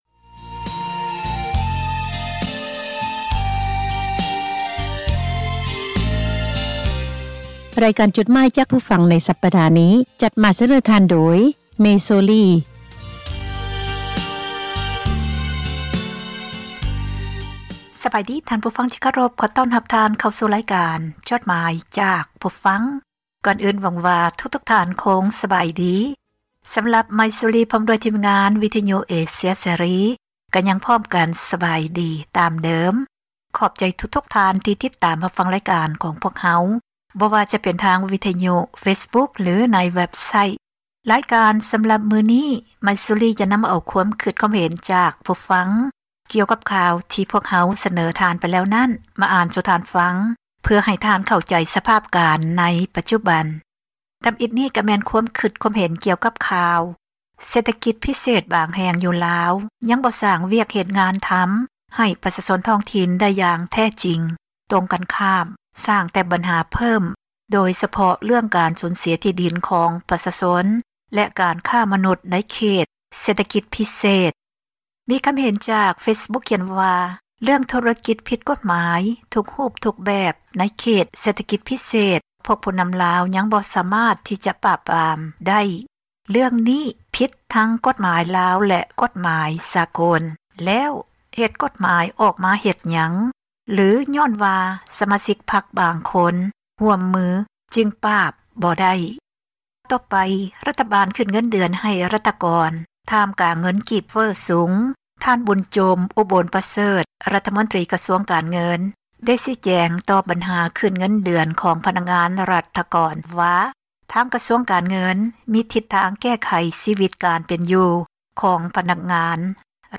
( ເຊີນທ່ານ ຟັງຣາຍລະອຽດ ຈາກສຽງບັນທຶກໄວ້) ໝາຍເຫດ: ຄວາມຄິດຄວາມເຫັນ ຂອງຜູ່ອ່ານ ທີ່ສະແດງອອກ ໃນເວັບໄຊທ໌ ແລະ ເຟສບຸກຄ໌ ຂອງວິທຍຸ ເອເຊັຽ ເສຣີ, ພວກເຮົາ ທິມງານ ວິທຍຸເອເຊັຽເສຣີ ໃຫ້ຄວາມສຳຄັນ ແລະ ຂອບໃຈ ນຳທຸກໆຖ້ອຍຄຳ, ແລະ ມີໜ້າທີ່ ນຳມາອ່ານໃຫ້ທ່ານ ໄດ້ຮັບຟັງກັນ ແລະ ບໍ່ໄດ້ເສກສັນປັ້ນແຕ່ງໃດໆ, ມີພຽງແຕ່ ປ່ຽນຄຳສັພ ທີ່ບໍ່ສຸພາບ ໃຫ້ເບົາລົງ ເທົ່ານັ້ນ. ດັ່ງນັ້ນ ຂໍໃຫ້ທ່ານຜູ່ຟັງ ຈົ່ງຕັດສິນໃຈເອົາເອງ ວ່າ ຄວາມຄິດເຫັນນັ້ນ ເປັນໜ້າເຊື່ອຖື ແລະ ຄວາມຈິງ ຫລາຍ-ໜ້ອຍ ປານໃດ.